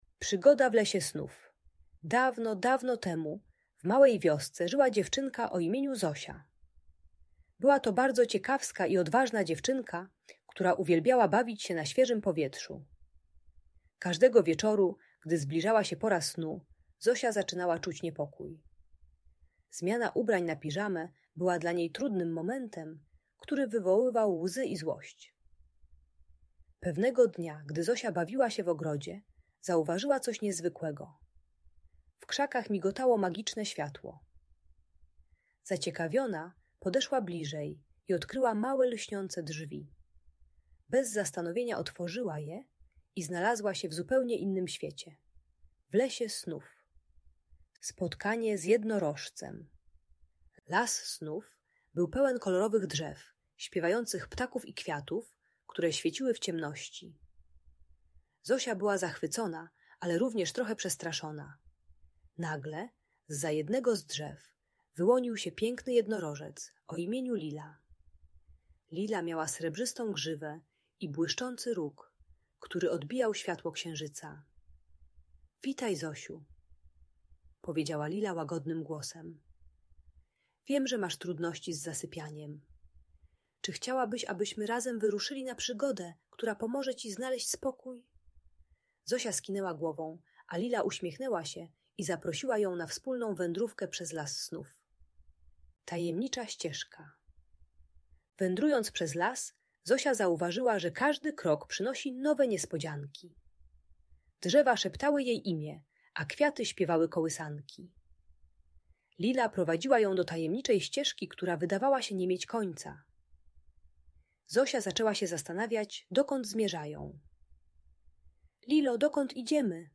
Przygoda Zosi w Lesie Snów - Usypianie | Audiobajka
Ta bajka dla dziecka które nie chce zasypiać uczy techniki wizualizacji uspokajającego miejsca. Audiobajka na dobranoc pomagająca w wieczornym rytuale zasypiania dla przedszkolaków.